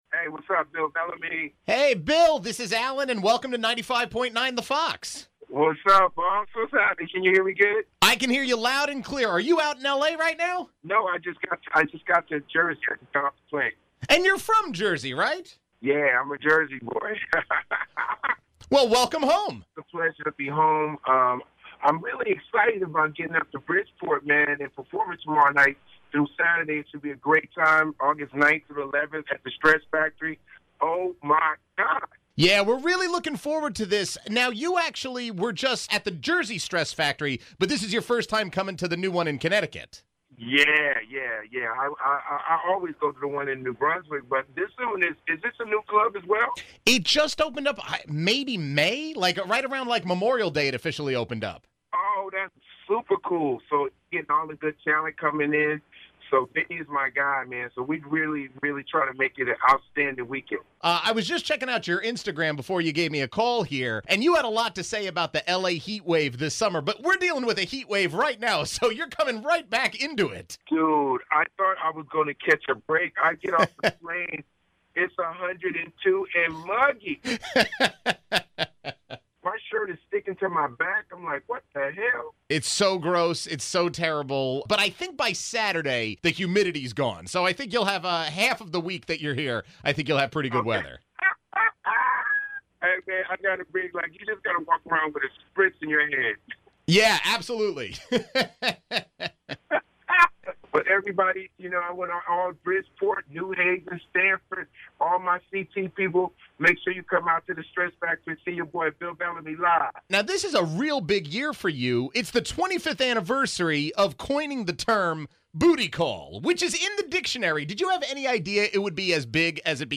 Facebook Twitter Headliner Embed Embed Code See more options This weekend, comedian Bill Bellamy is coming to Vinnie Brand's Stress Factory in Bridgeport. He stopped by First Thing Fairfield County to chat